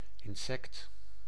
Ääntäminen
Ääntäminen Tuntematon aksentti: IPA: /ɪn.ˈsɛkt/ Haettu sana löytyi näillä lähdekielillä: hollanti Käännös Konteksti Substantiivit 1. hyönteinen eläintiede Muut/tuntemattomat 2. lude 3. ötökkä Suku: n .